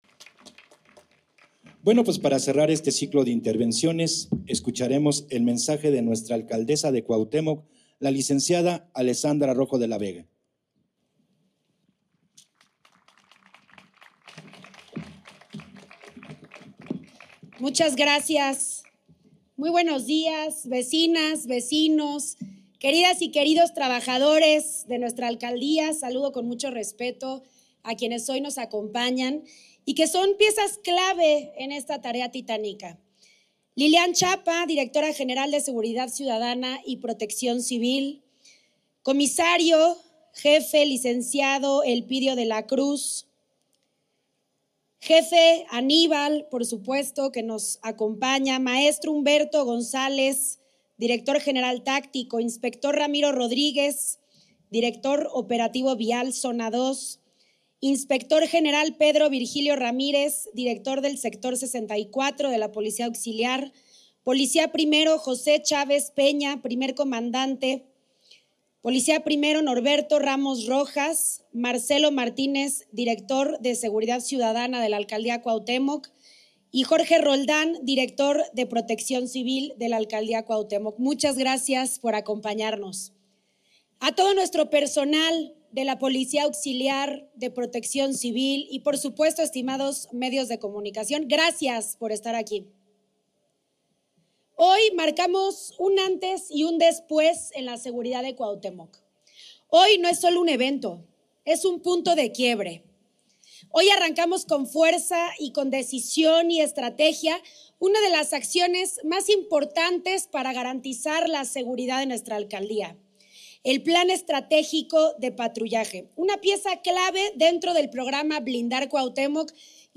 En un acto realizado en la explanada de la alcaldía, la mandataria presentó los nuevos vehículos que se integran a la estrategia Blindar Cuauhtémoc: 14 patrullas, 28 motopatrullas, 6 motocicletas de Protección Civil, 4 unidades de primer contacto y una ambulancia.